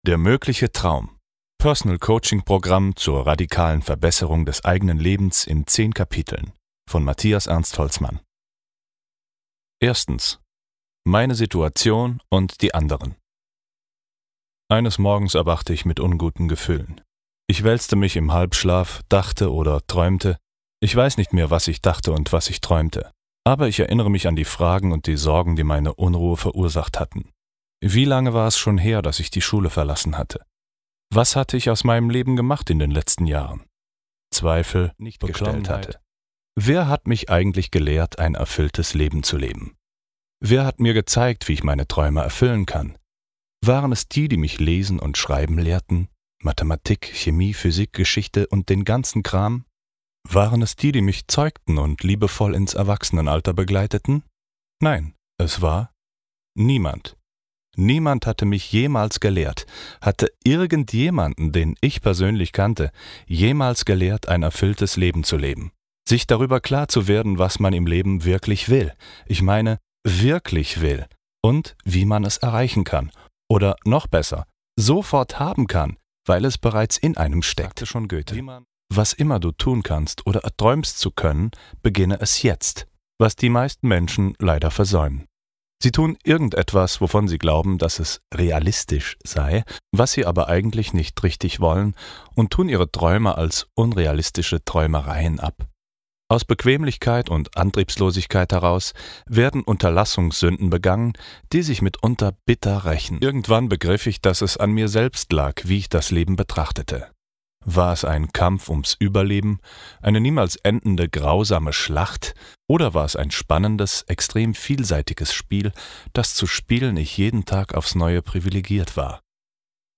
Dieses Hörbuch ermöglicht es Dir!